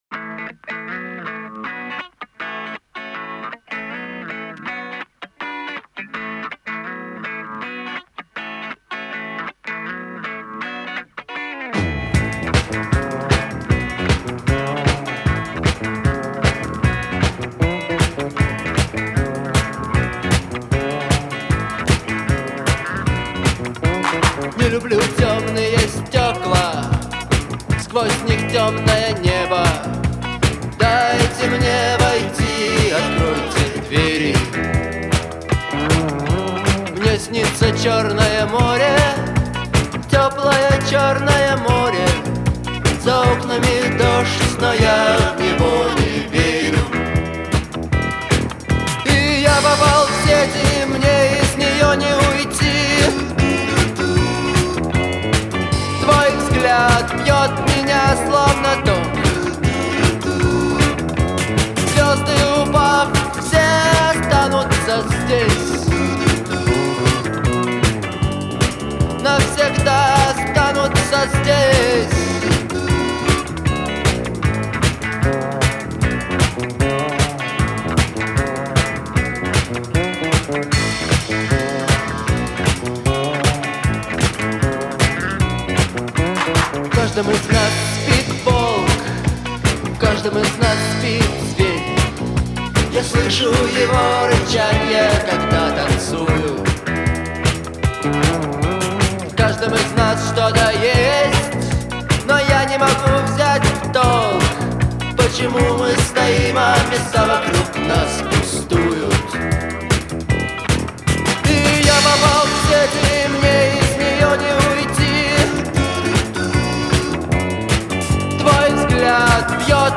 характерными гитарными рифами